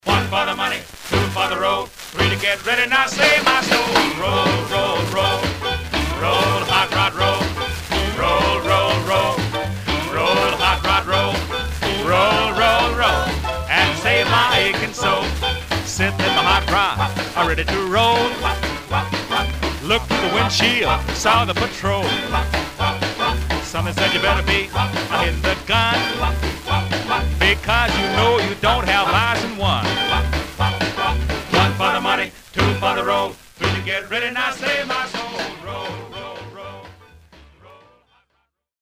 Surface noise/wear
Mono
Rythm and Blues